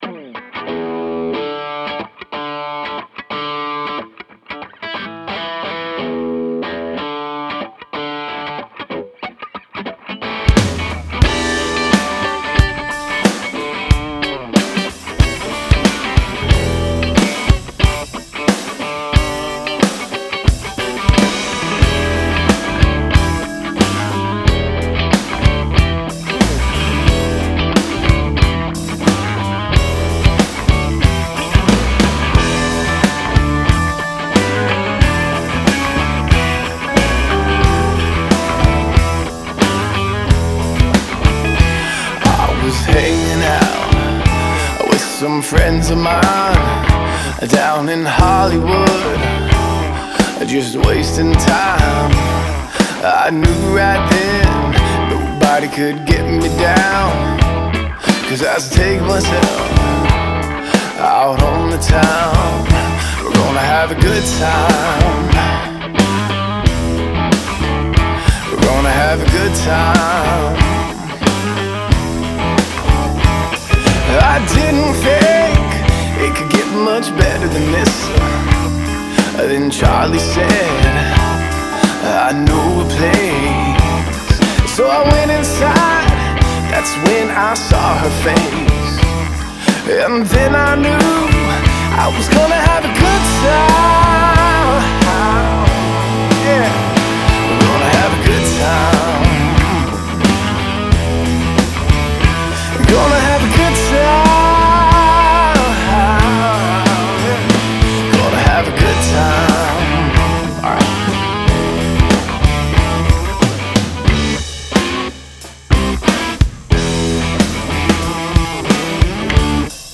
Барабанщик конечно бухой видимо)... темпа композы не знаю